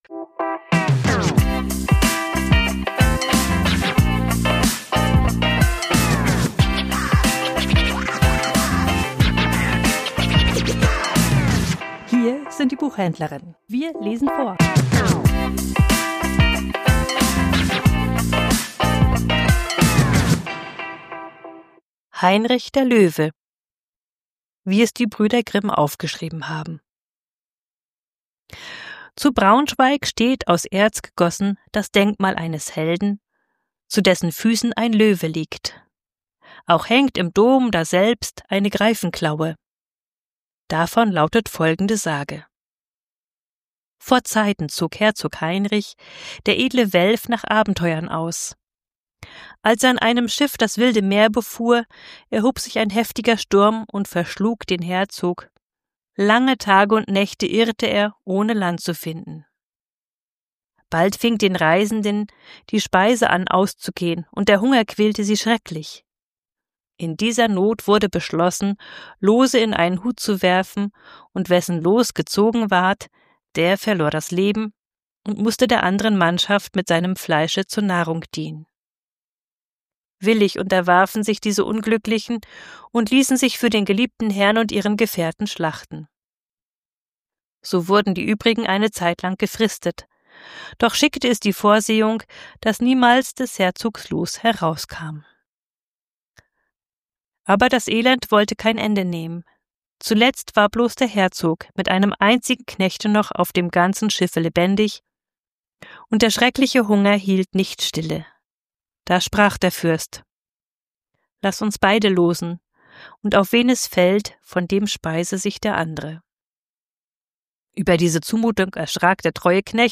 Vorgelesen: Heinrich der Löwe ~ Die Buchhändlerinnen Podcast